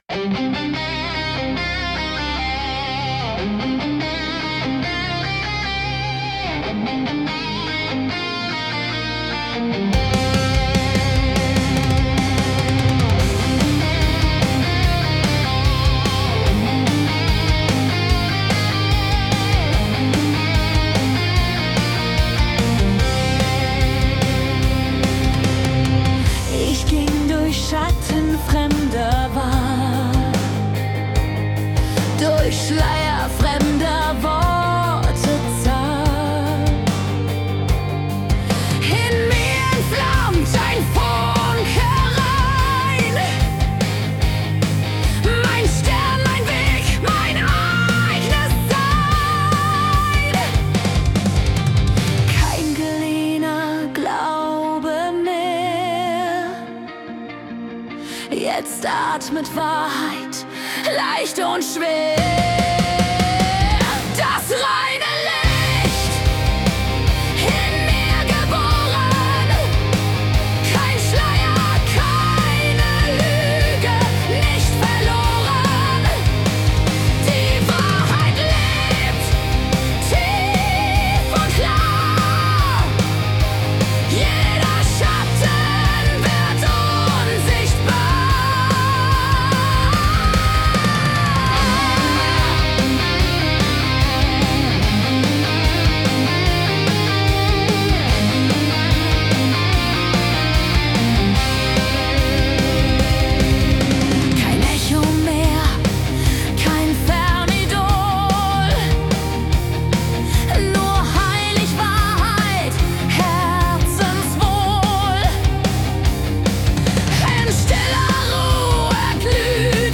Melodic Heavy MetalBPM ~148